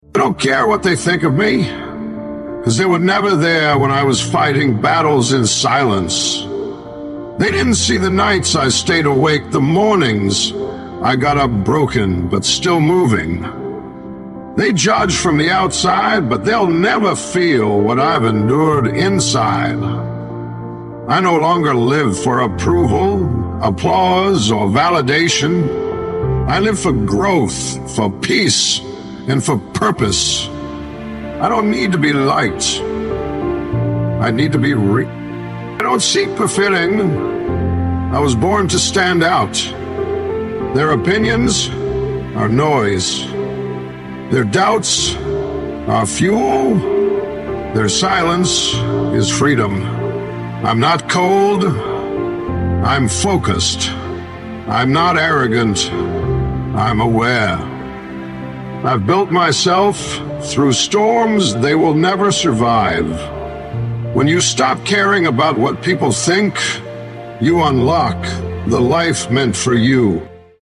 💪 🔊 2x 15” subwoofers ⚡ 1x Starsound 13K 🔋 10x XS Power Titan 8 lithiums This score has NEVER been done before in the IASCA clamped classes — a first of its kind, a moment for the history books!